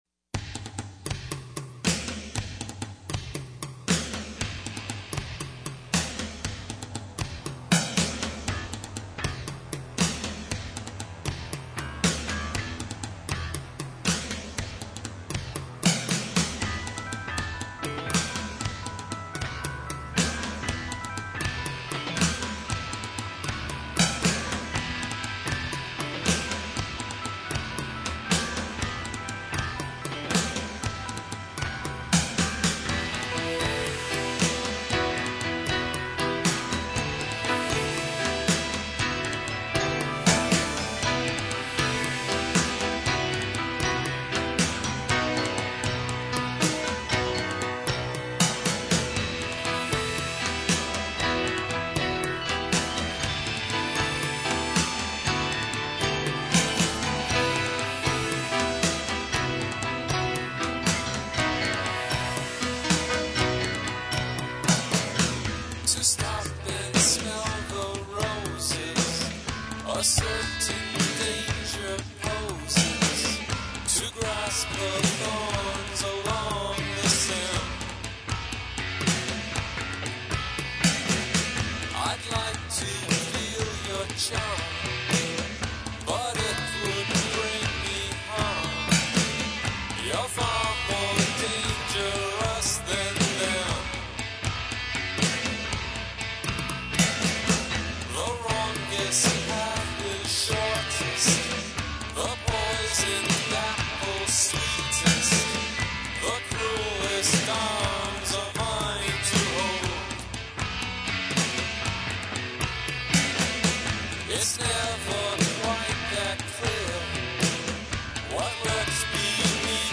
Programming
Guitars
Lead Vocals
Background Vocals